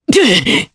Roi-Vox_Damage_jp_02.wav